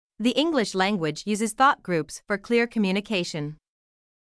1. 1つのThought Groupの中で1つの単語にストレスが置かれ、他の単語のストレスは弱まる
※当メディアは、別途記載のない限りアメリカ英語の発音を基本としています